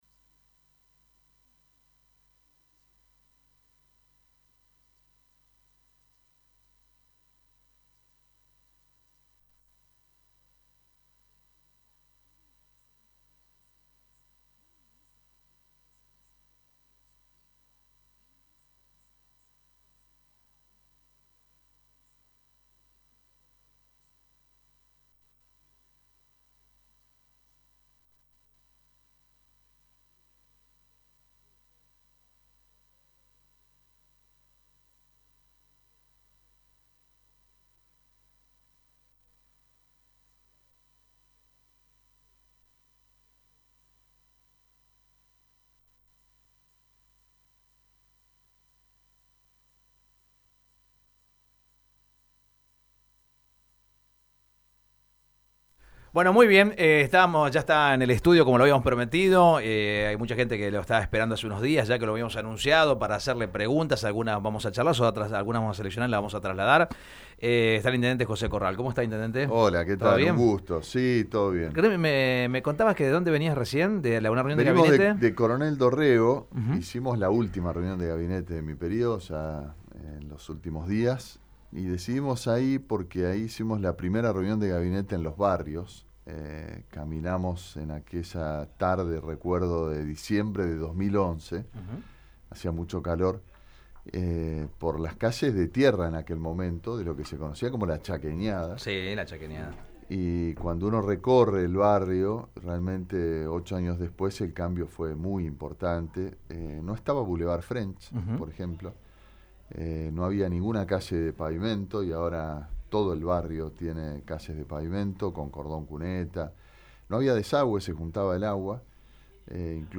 El Intendente de la Ciudad de Santa Fe visitó los estudios de Radio EME, y realizó un balance de su gestión municipal.
Este jueves, el Intendente de la Ciudad de Santa Fe, José Corral, visitó los estudios de Radio EME. En dialogo con el equipo de «La Mañana Menos Pensada», el Jefe de la Municipalidad realizó un balance de su gestión.